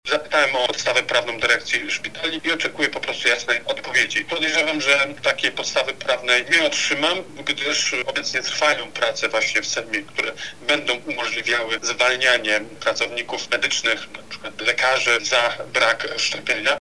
-mówi poseł Jakub Kulesza przewodniczący Koła Poselskiego Konfederacja